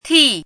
注音： ㄊㄧˋ
ti4.mp3